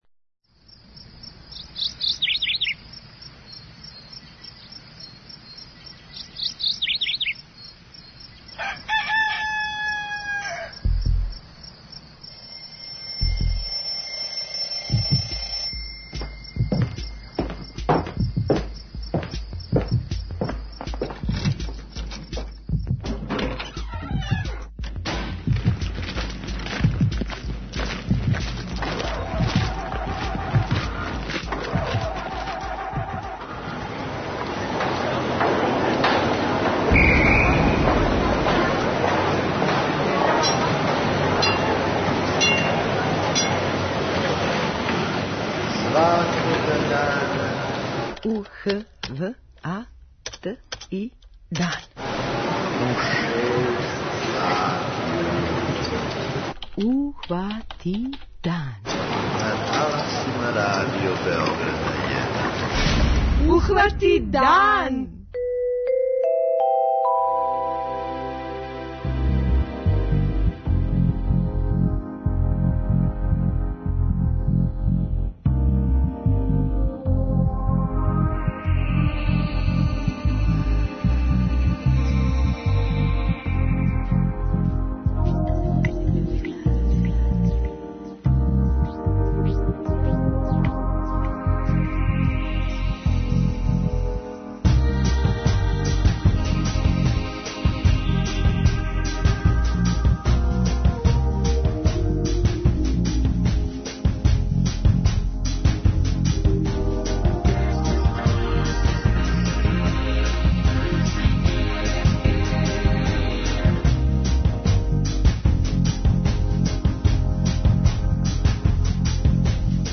06:30 Догодило се на данашњи дан, 07:00 Вести, 07:05 Добро јутро децо, 08:00 Вести, 08:05 Српски на српском, 08:15 Гост јутра